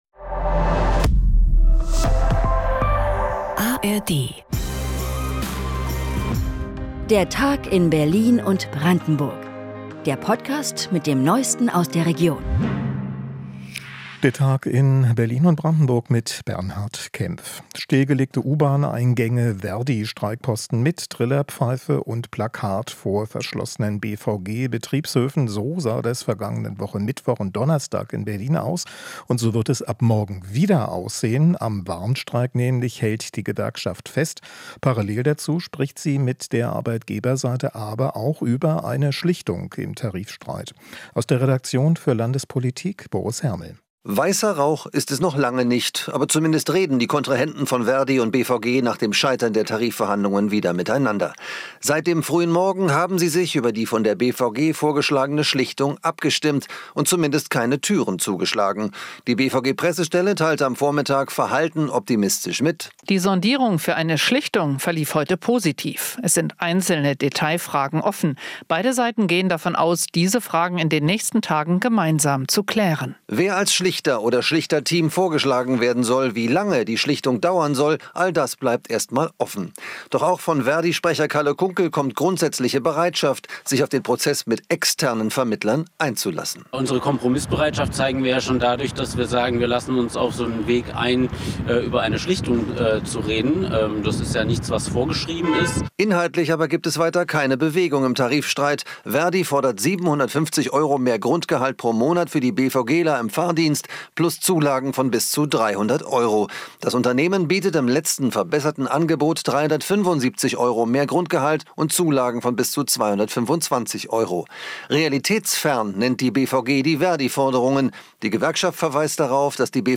Quebramos o nosso hiato pra falar sobre sua obra, legado e as características que mais apreciamos na sua escrita e criação de mundos. O podcast foi gravado presencialmente em14 de outubro de 2023, na Livraria da Vila em São Paulo…